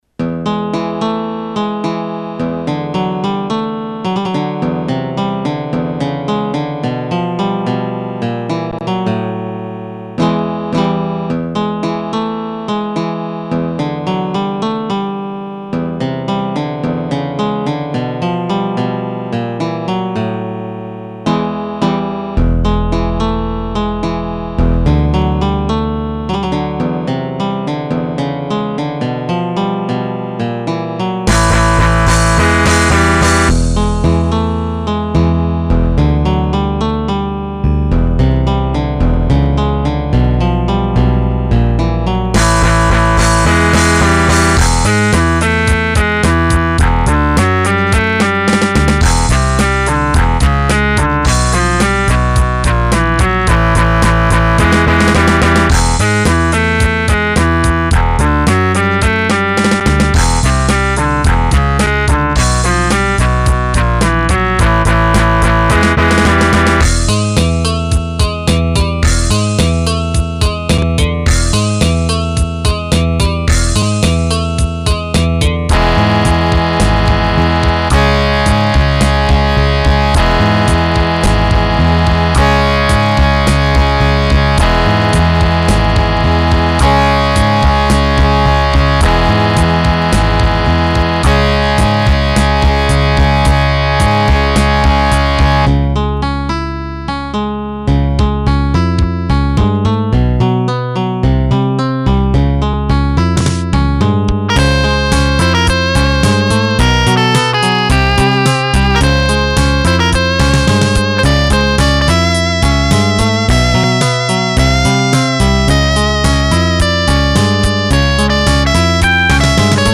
Y estan en MIDI, algun dia estaran grabadas.